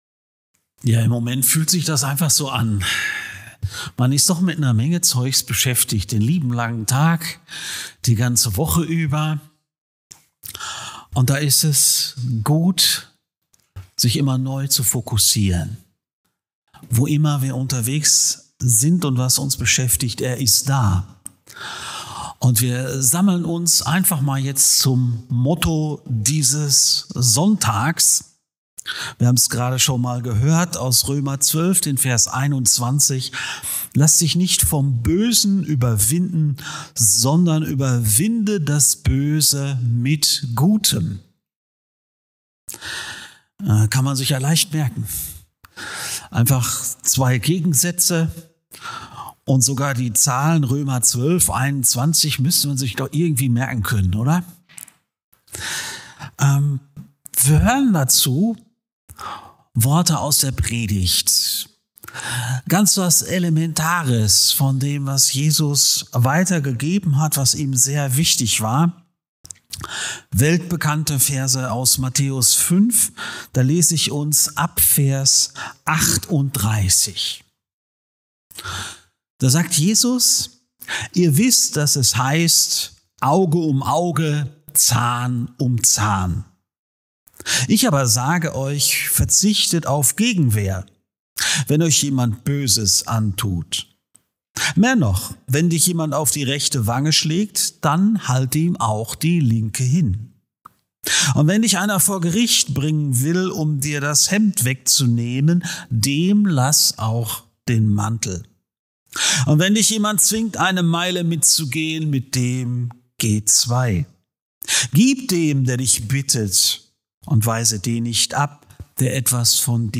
Predigt Podcast